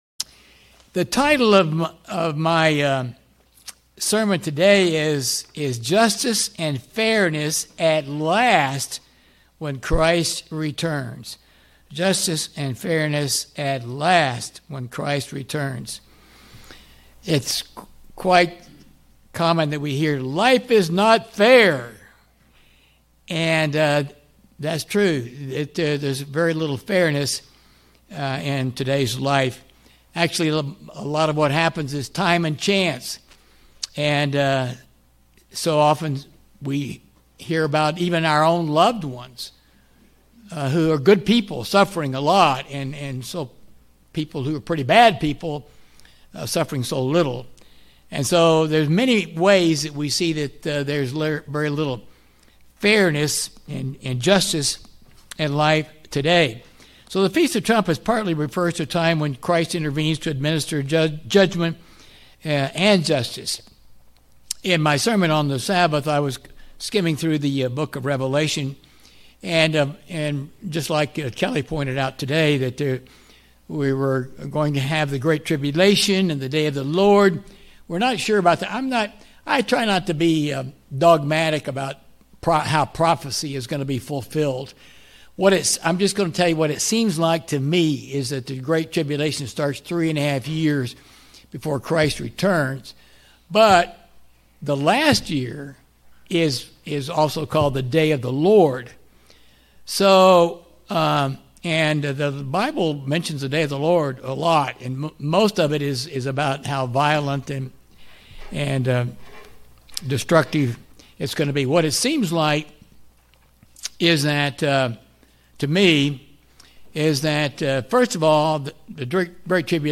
Sermons
Given in Springfield, MO Northwest Arkansas